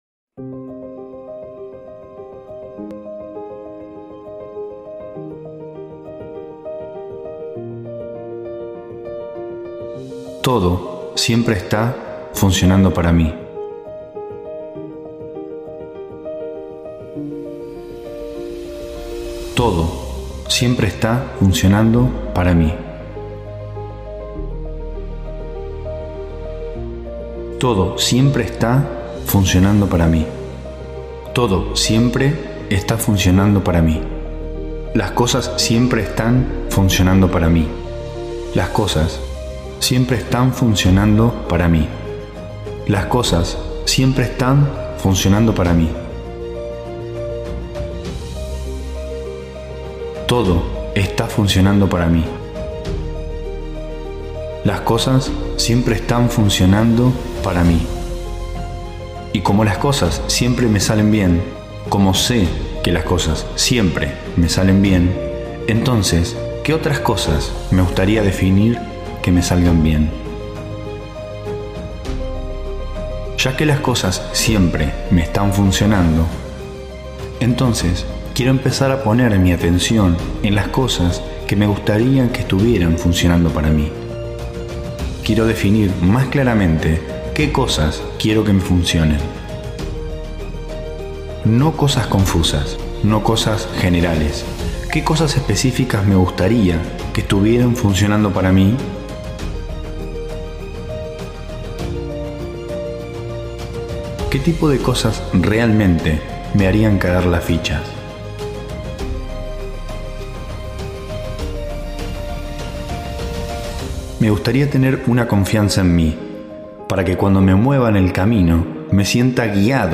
Mi adaptación de una meditación de Abraham-Hicks. Escuchalo siempre que tengas pensamientos y sentimientos de culpa, miedo o frustración.